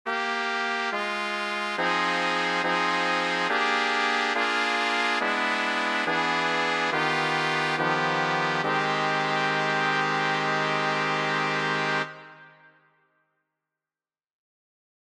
Key written in: A♭ Major
How many parts: 4
Type: Barbershop
All Parts mix: